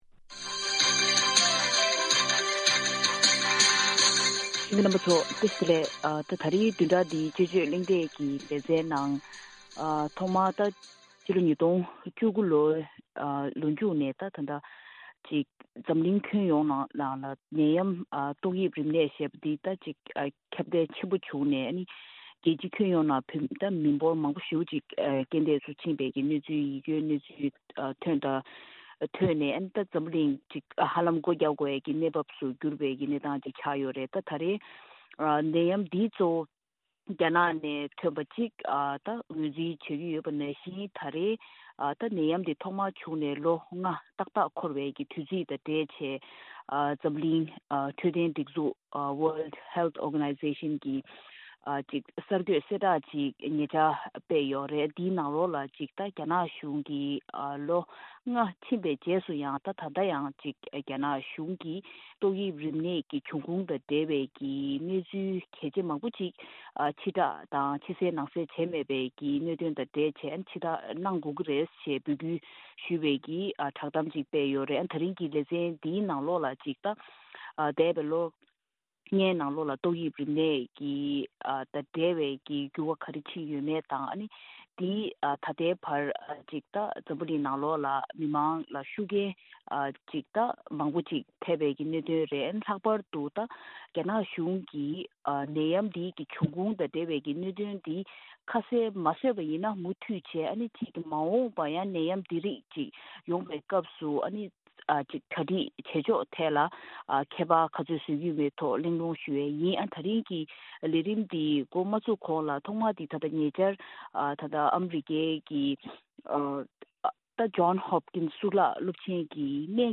ཐེངས་འདིའི་བདུན་རེའི་དཔྱད་བརྗོད་གླེང་སྟེགས་ཀྱི་ལས་རིམ་ནང་།